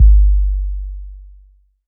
Kick Elektro 2.wav